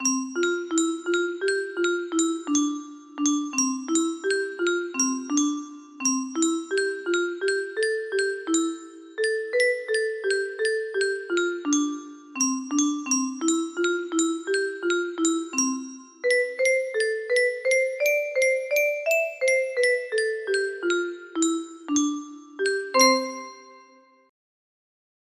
It's only an hour away music box melody
Ti.sig.: 4/4
Temp.: 85 (I tried to do a ritardando at the end without it sounding like randomly changed tempo)
Key: C maj